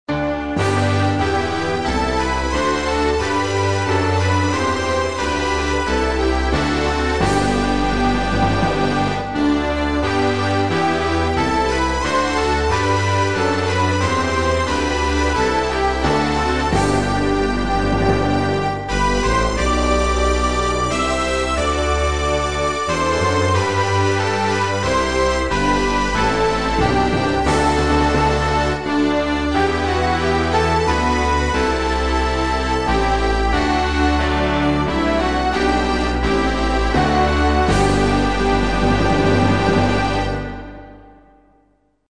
Inno nazionale